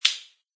plasticplastic.mp3